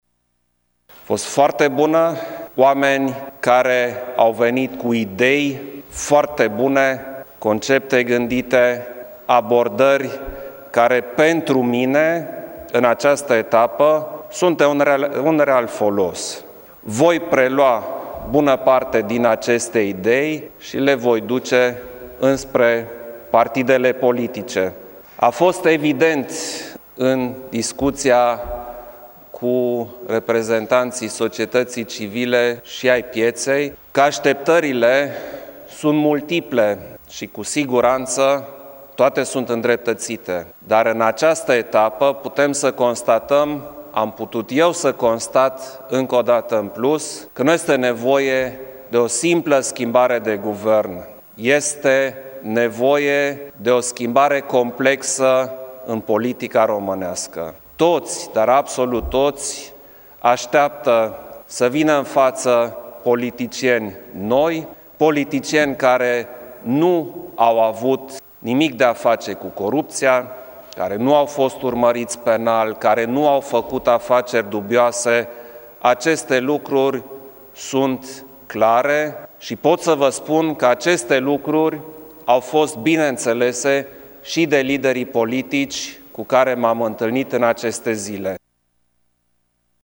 Președintele a făcut în urmă cu puțin timp declarații referitoare la consultările de astăzi, în special cele cu reprezentanții societăţii civile: